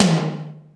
HR16B  TOM 1.wav